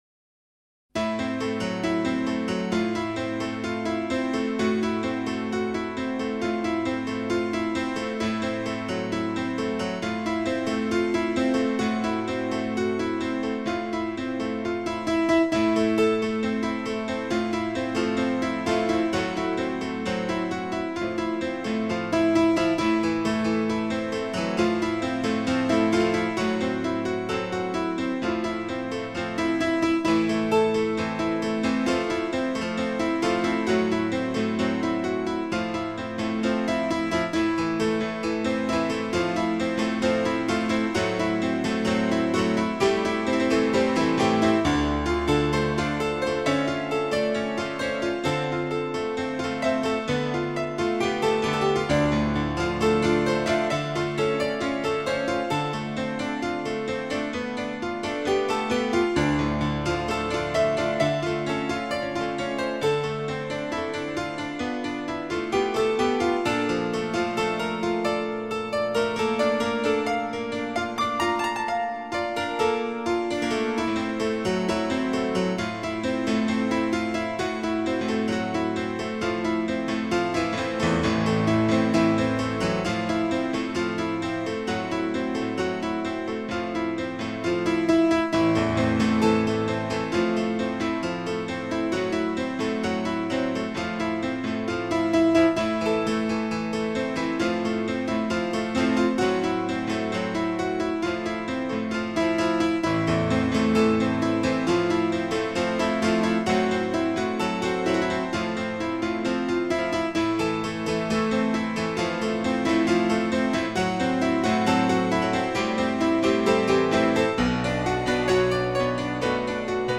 僅低音質壓縮 , 供此線上試聽